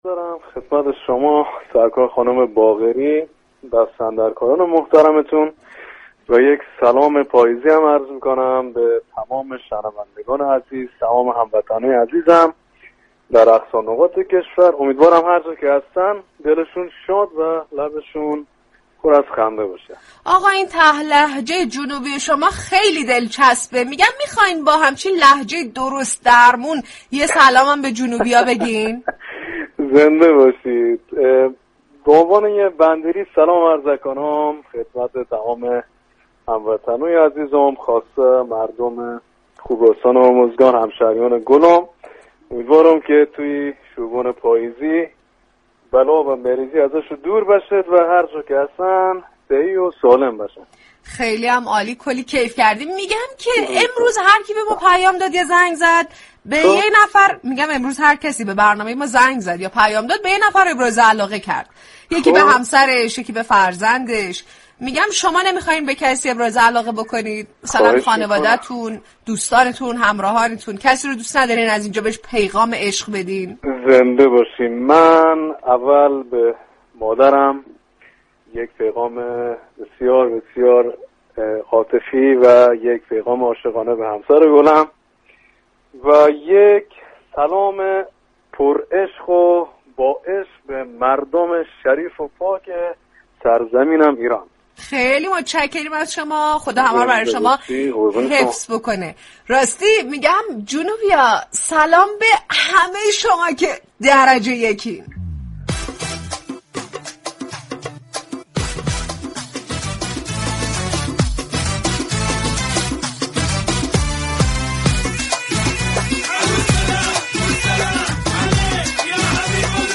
عمران طاهری خواننده ترانه های بندری در گفتگو با رادیو صبا از علاقه اش به شغل نجاری گفت.